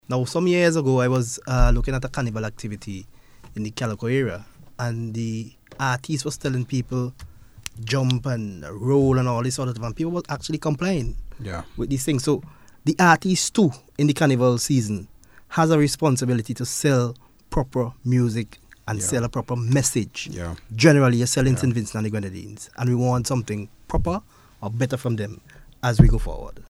Speaking on the On The Beat programme last evening